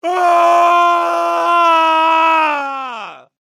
Screaeam Sound Button - Free Download & Play